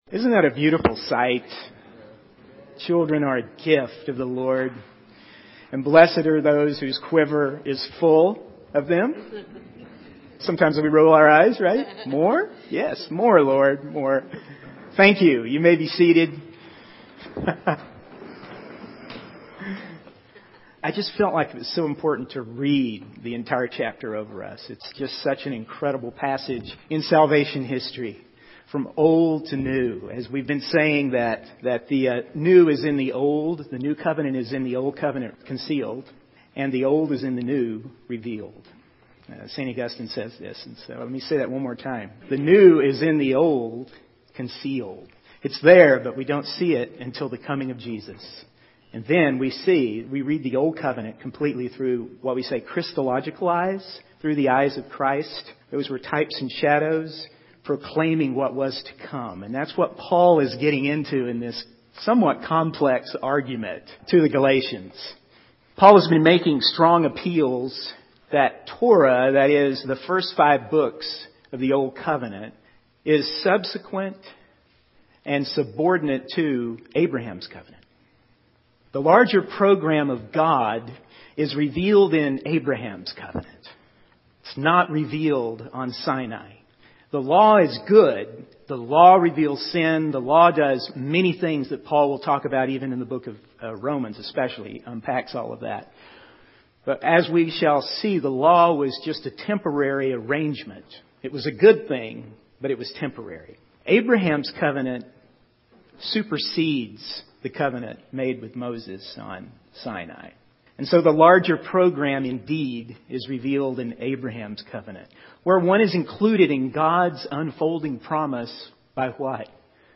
In this sermon, the speaker discusses the concept of being an heir and the role of the law as a temporary custodian for the nation of Israel. He explains that while an heir is underage, they are no different from a slave, even though they own the entire estate.